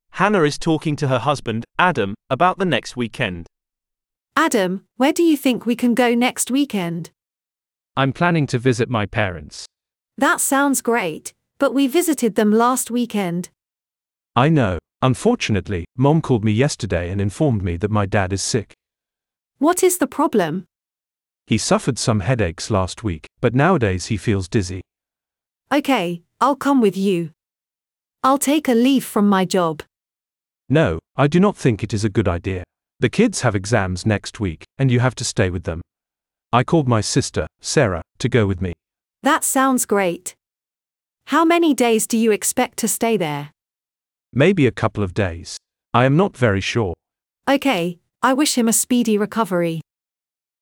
Part 1: Listen to a speaker talk about the Museum of the Future and complete the sentences.